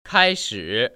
[kāishǐ]
카이 스(sh 혀를 굴려서)
특히 sh 발음은 r발음 하듯이 굴려서 해주셔야 해요.